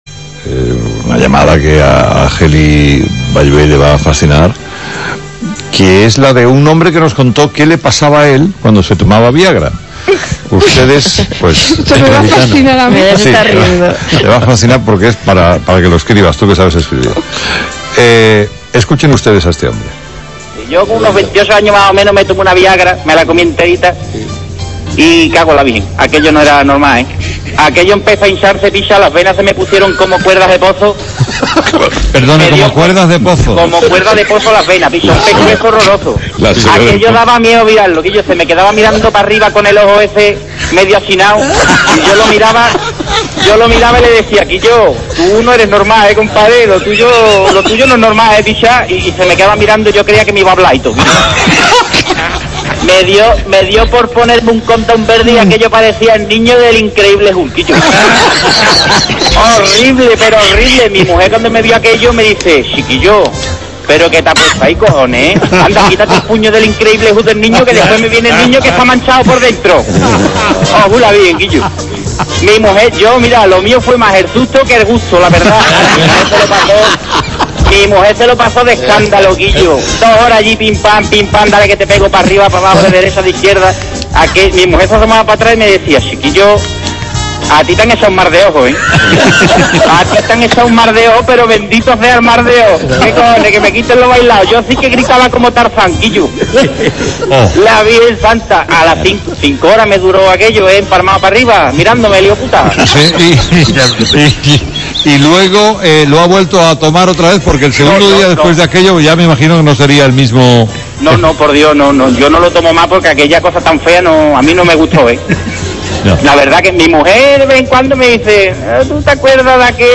Esto es una llamada de un hombre que explica en el programa de radio de Carlos Herrera, lo que le pasó una noche que decidió ponerse a tono. El hombre lo explica todo con mucho salero.
Jajajaja, al tío se le nota que es de Cádiz, mucho ‘pisha’ (o habría que decir ‘pishon’ :P) suelta de vez en cuando.